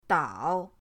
dao3.mp3